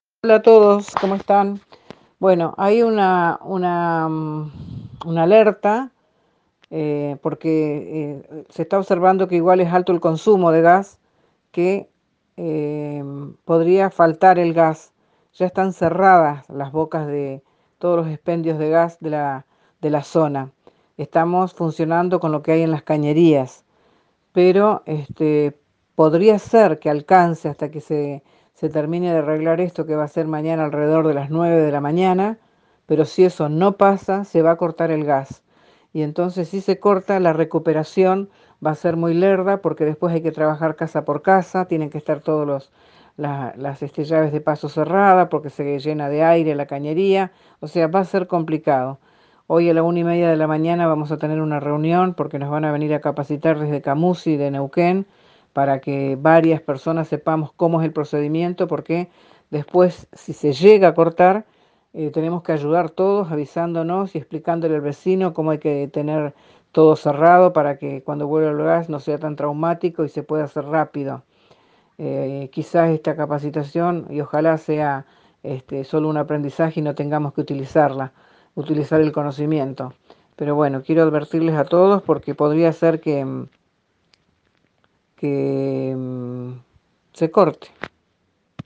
A través de WhatsApp se difundió un audio de la intendenta Brunilda Rebolledo en el que explica la posible situación de que se corte la provisión de gas.